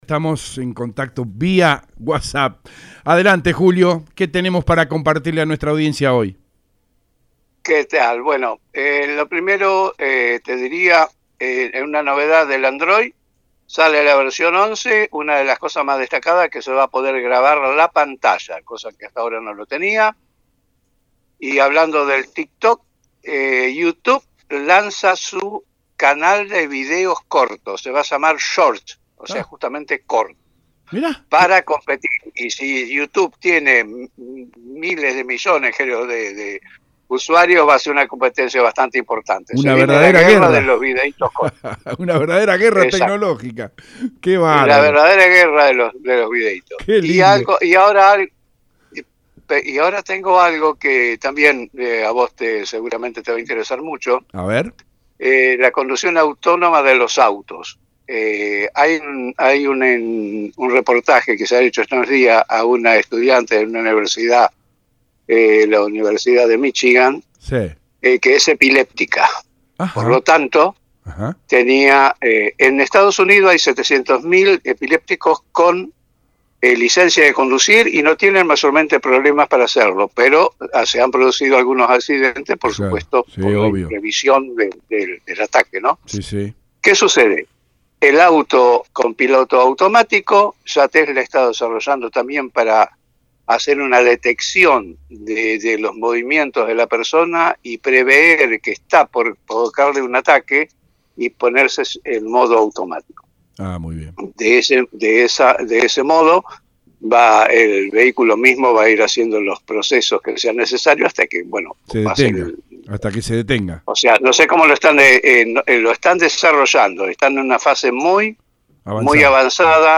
Esto se habló en radio EL DEBATE, en el ultimo programa del invierno.